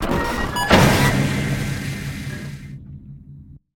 DoorClose079.ogg